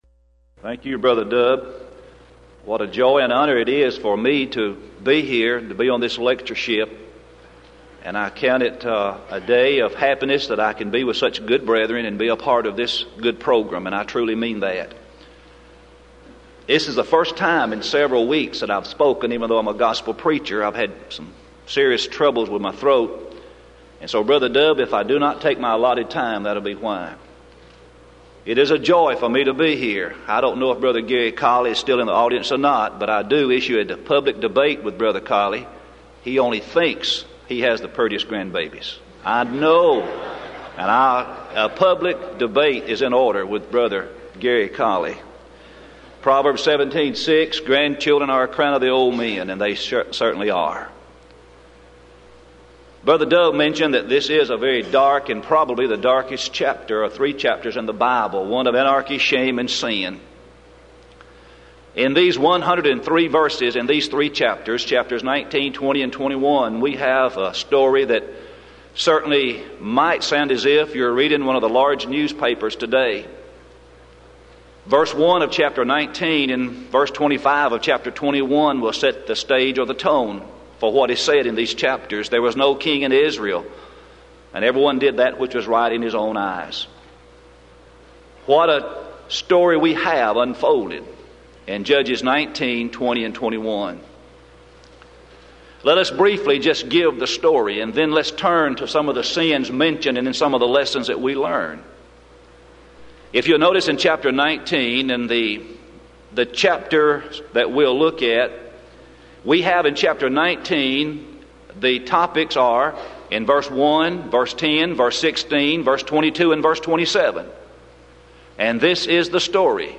Event: 1994 Denton Lectures